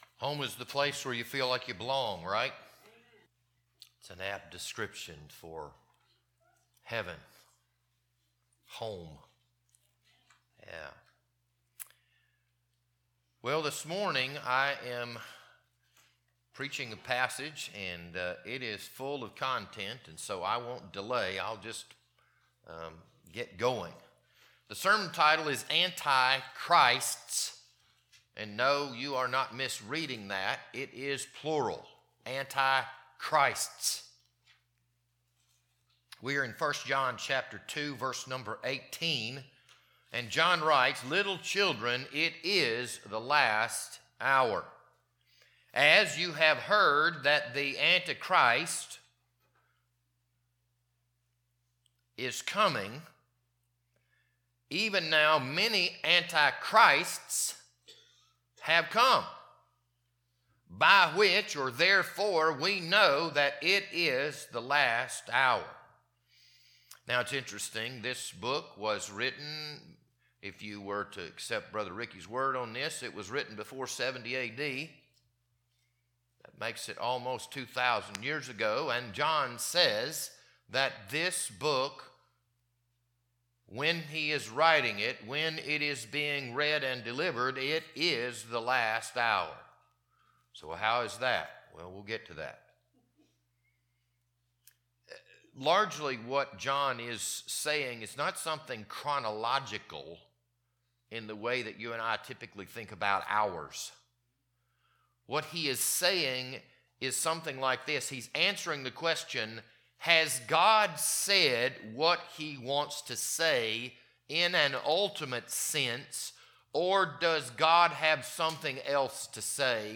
This Sunday morning sermon was recorded on March 15th, 2026.